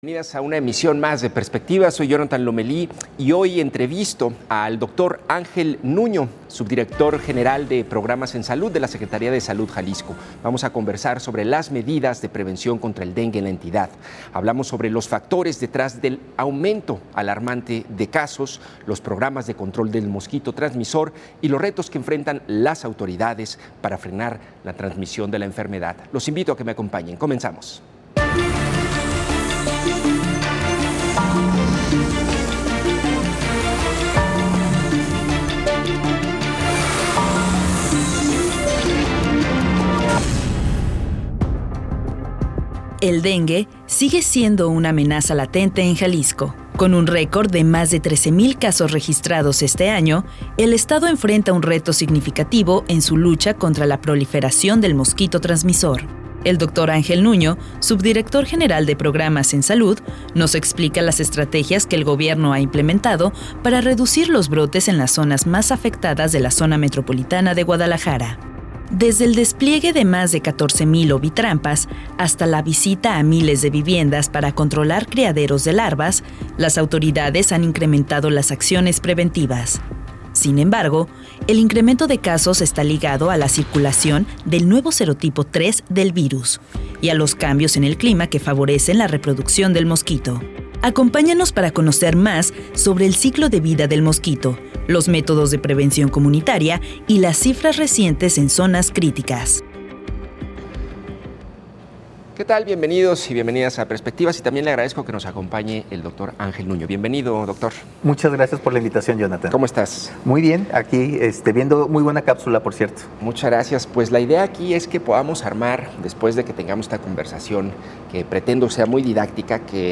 Entrevistamos al Dr. Ángel Nuño, Subdirector General de los Servicios de Salud Jalisco